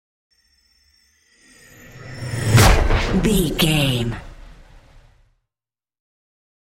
Trailer dramatic whoosh to hit
Sound Effects
Fast paced
In-crescendo
Atonal
dark
intense
tension
woosh to hit